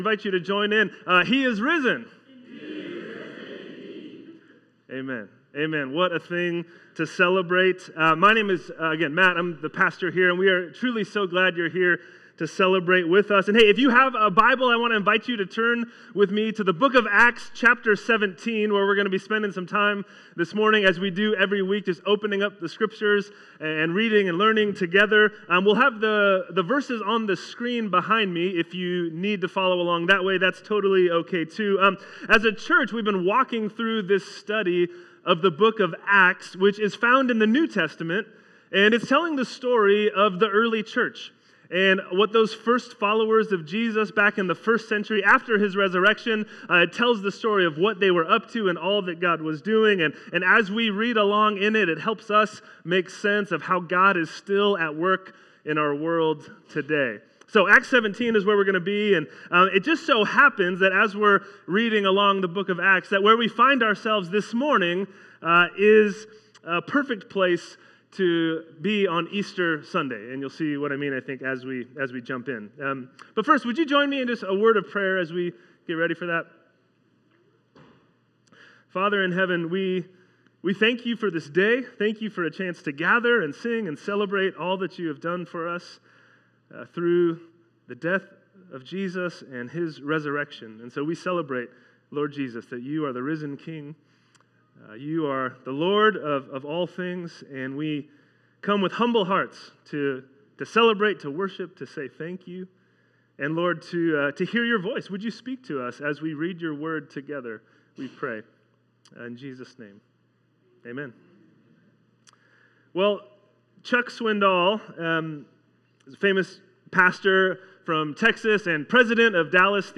Sermons | First Baptist Church of Benicia